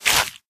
脚步声
CR_fm_step_01.mp3